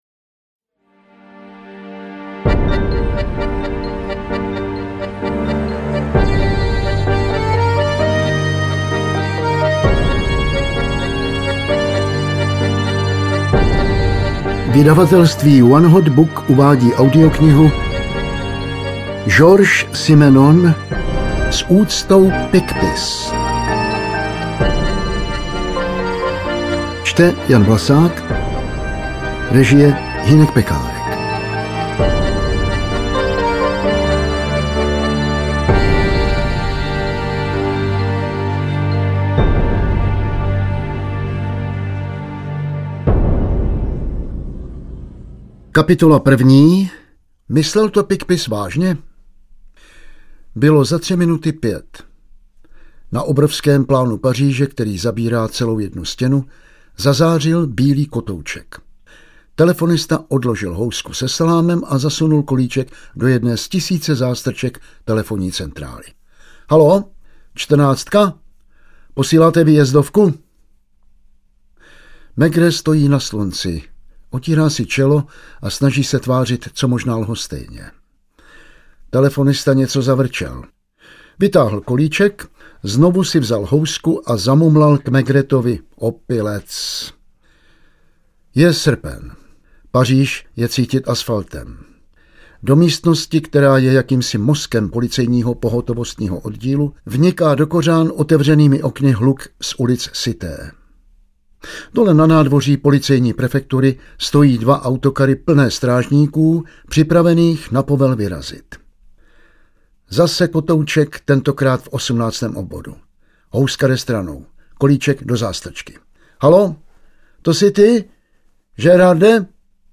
Interpret:  Jan Vlasák